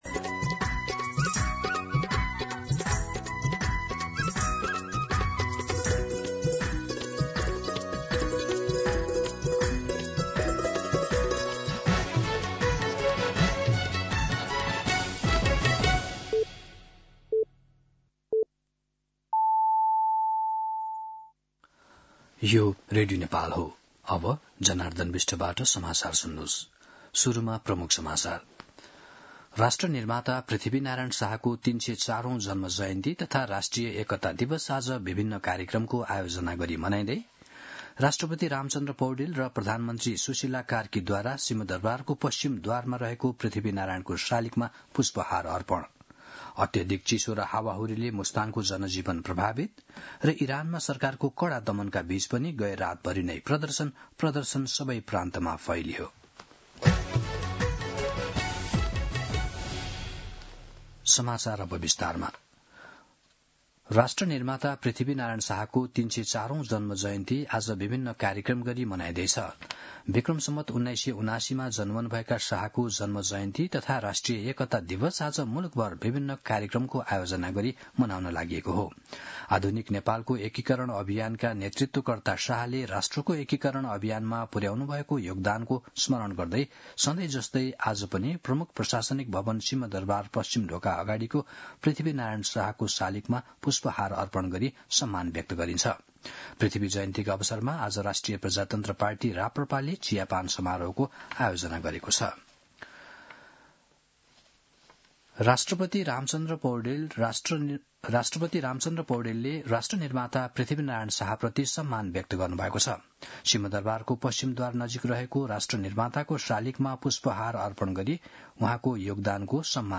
दिउँसो ३ बजेको नेपाली समाचार : २७ पुष , २०८२
3-pm-Nepali-News-2.mp3